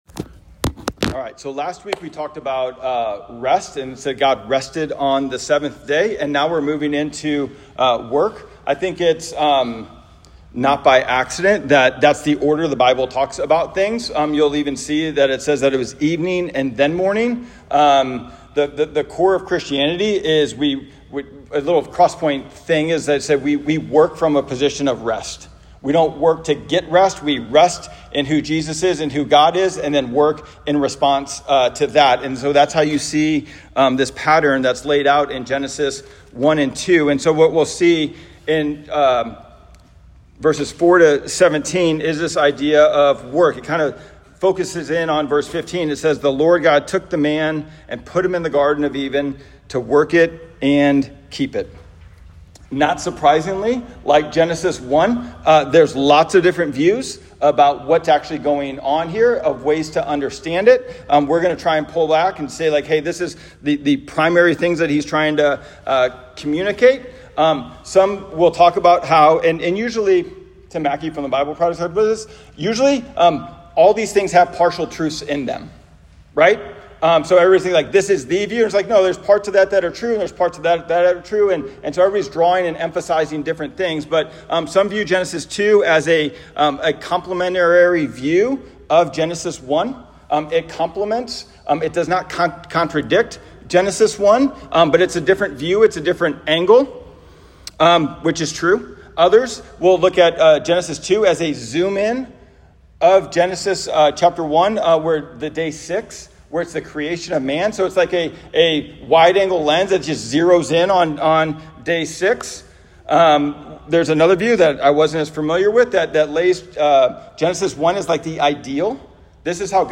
Genesis-2.4-17_Sermon-Audio-1.m4a